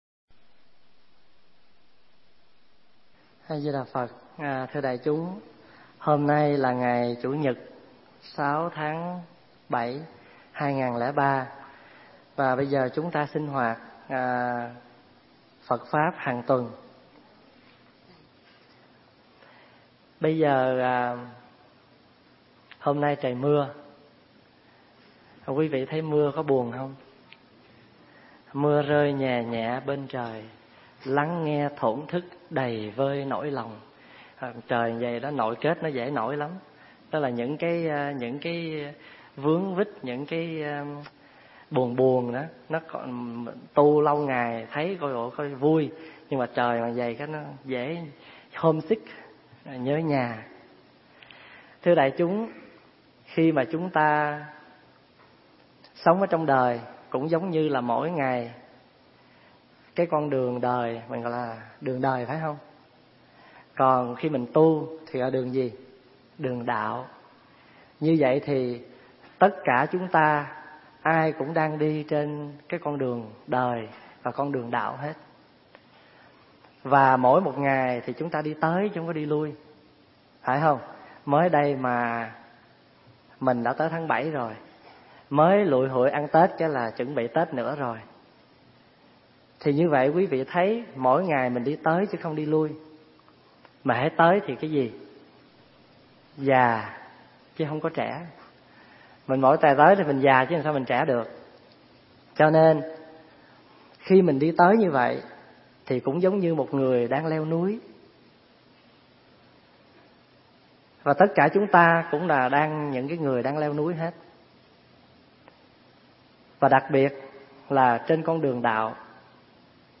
Tải mp3 Pháp Âm Hóa Giải Chướng Duyên 1 – Đại Đức Thích Pháp Hòa thuyết giảng ngày 6 tháng 7 năm 2003